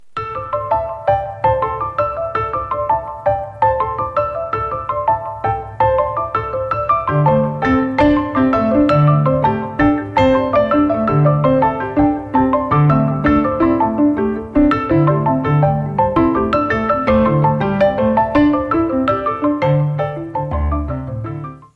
• Качество: 112, Stereo
Стандартный рингтон